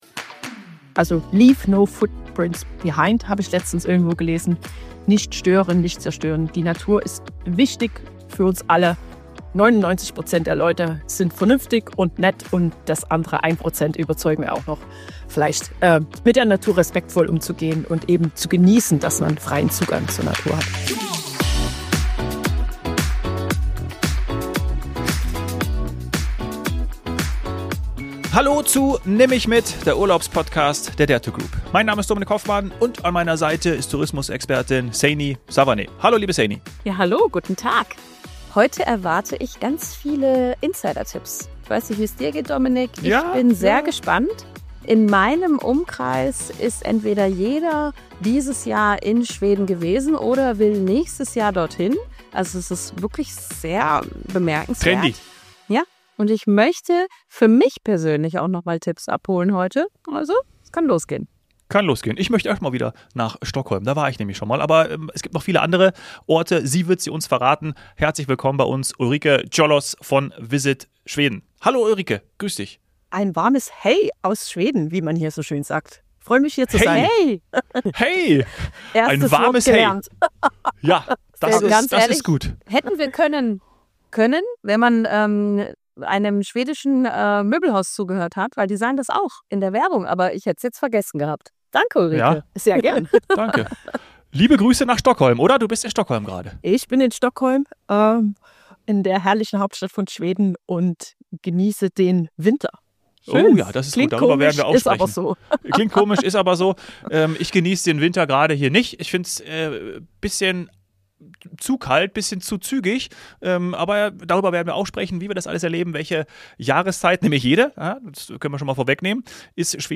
Gemeinsam sprechen die drei darüber, was Schweden so einzigartig macht – von Nachhaltigkeit bis zu kulinarischen Hochgenüssen, Outdoor-Abenteuern und kleinen Momenten, die man nur hier findet - in Schweden.